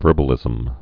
(vûrbə-lĭzəm)